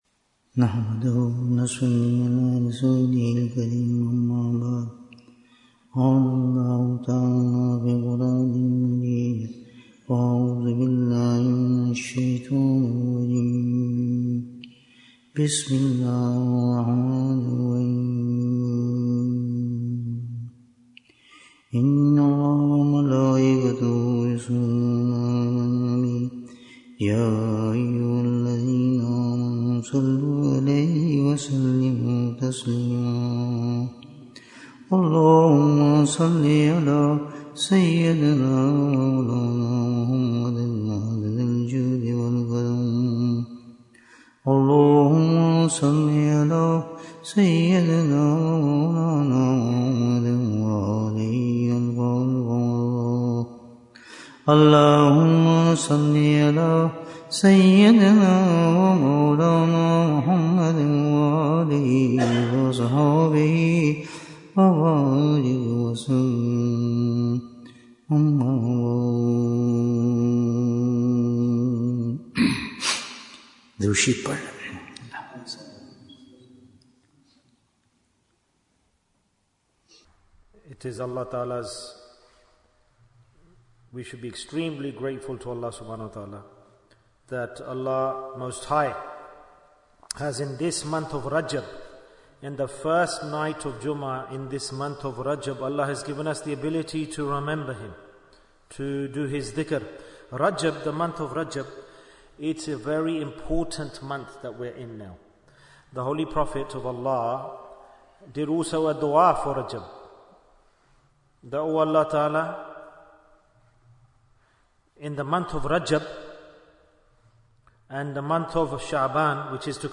Which Deed Should We Do In Rajab? Bayan, 60 minutes2nd January, 2025